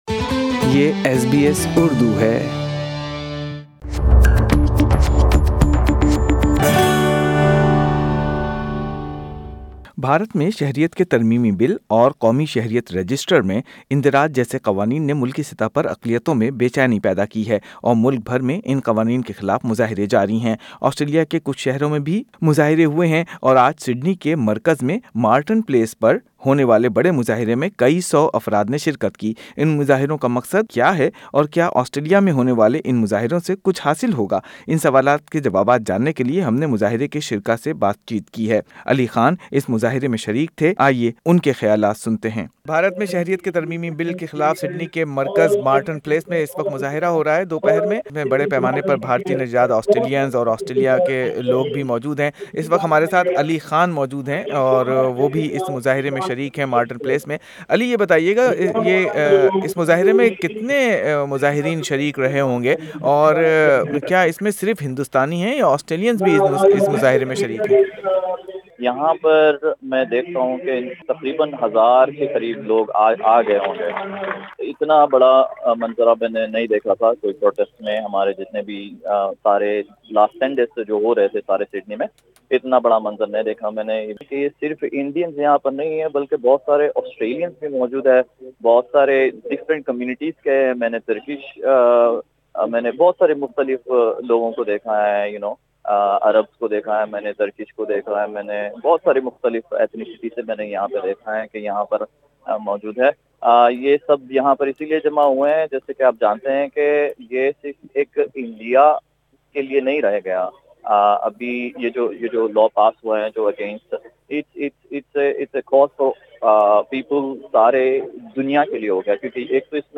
Hundreds of protesters gathered in Sydney CBD to protest against Indian citizenship amendment (ICA) and National register of citizenship (NRC). Speakers denounced alleged brutality and use of excessive force against peaceful demonstrators in India.